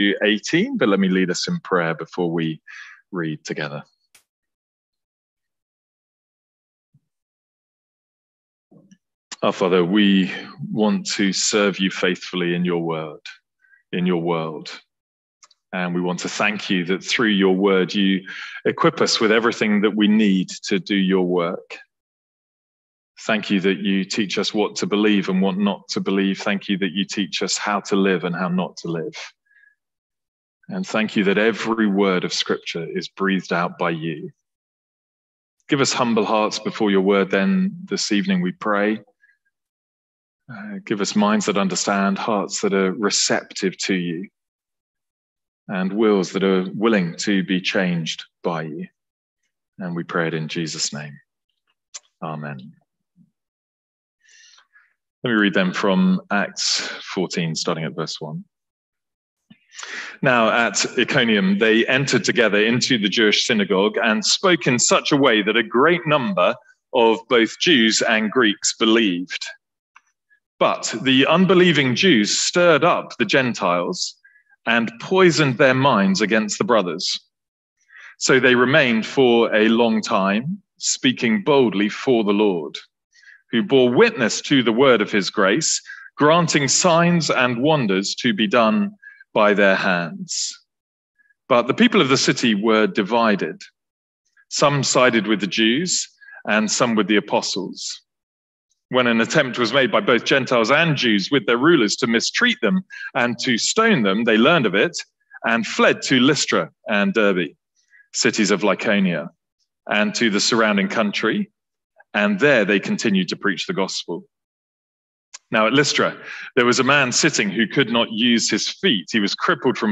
Sermons | St Andrews Free Church
From our evening series in Acts.